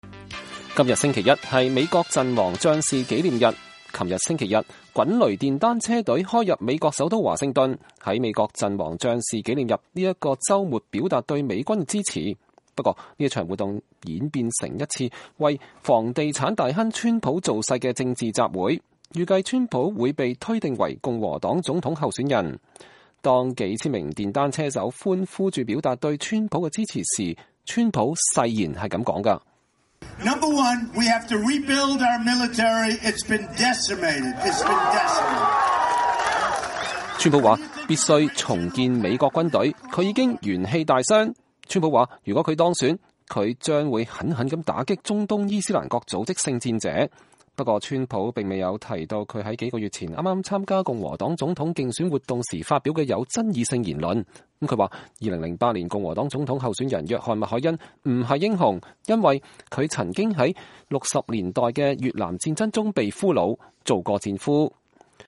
川普對支持者和滾雷電單車隊的人講話
2016-05-30 美國之音視頻新聞: 悼念陣亡將士滾雷活動變身川普競選集會
當數千名電單車手歡呼著表達對川普的支持時，川普誓言說：“我們必須重建我們的軍隊，它已經元氣大傷。”